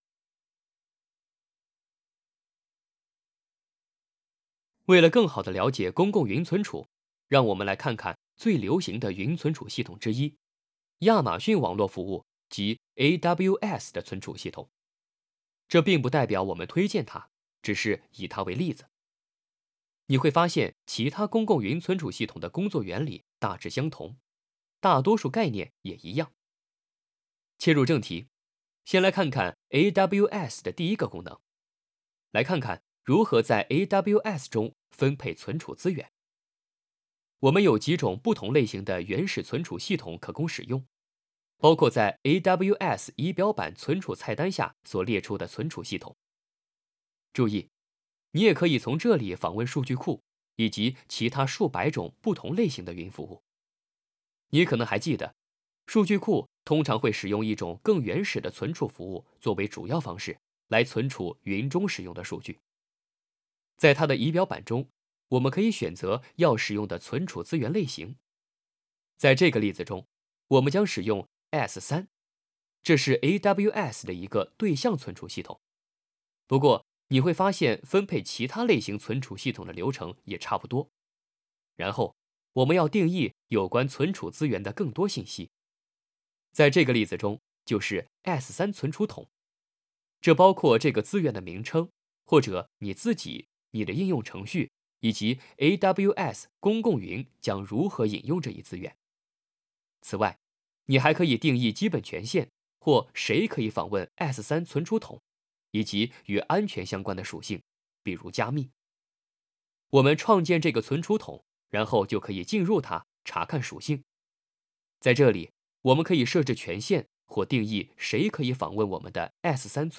Chinese_Male_023VoiceArtist_11Hours_High_Quality_Voice_Dataset